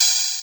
Gamer World Open Hat 1.wav